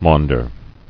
[maun·der]